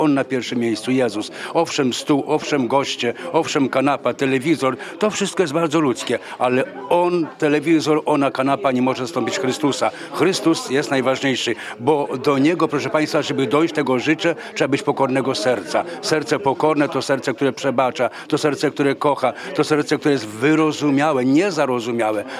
Znamienici goście pojawili się na wigilii Radia 5 w Suwałkach.